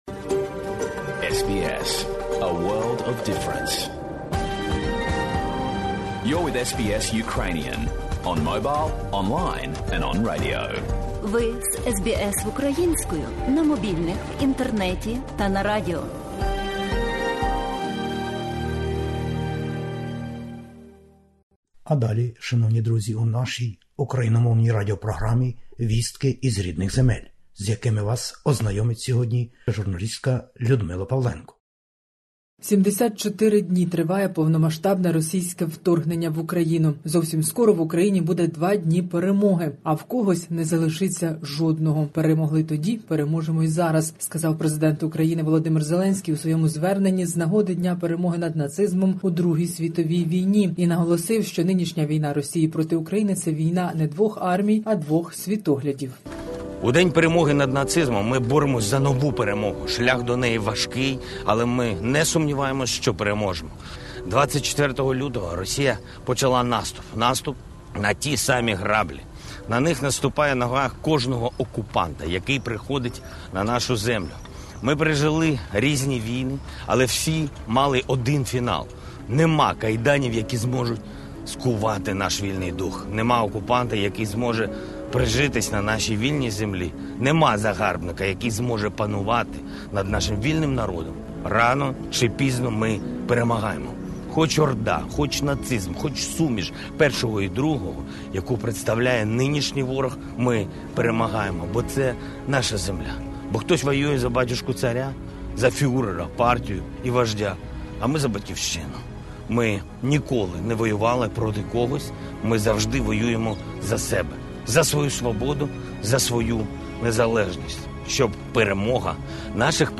Добірка новин із героїчної України спеціально для SBS Ukrainian.